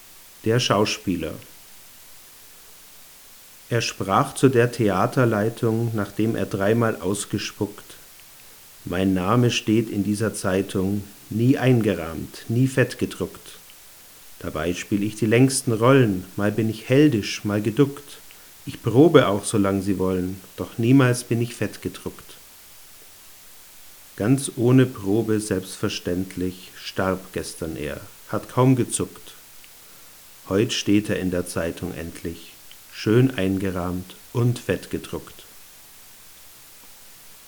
Zoom H5 PreAmp-Rauschen im Vergleich
Um das gefürchtete Rauschen zu vergleichen, habe ich ein dynamisches Mikrofon benutzt und aus 60 cm Abstand besprochen.
Anschließend wurden alle Aufnahmen noch normalisiert, damit ein echter Vergleich des Rauschens möglich ist.
Mikrofon direkt am Mic-Eingang des Recorders Tascam DR-40
tascam_dr40.mp3